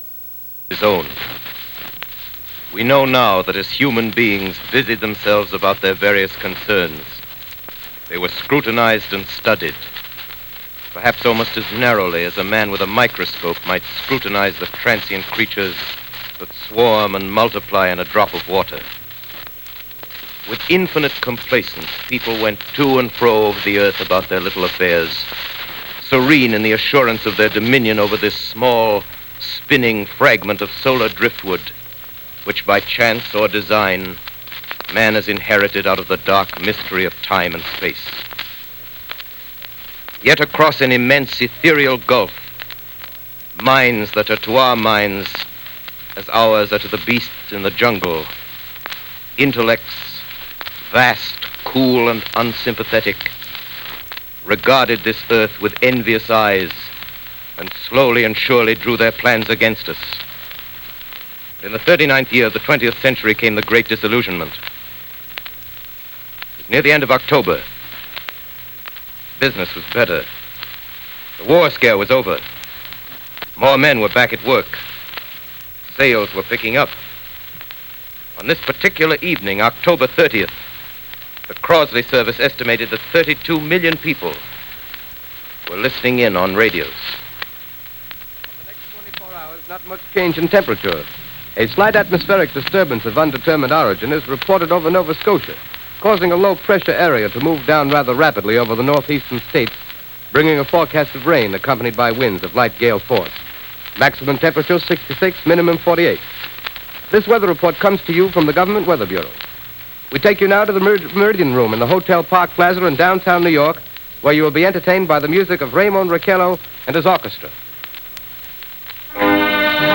The actual Radio Broadcast Our recording when we were, Like, 24 The Awesome outtakes!!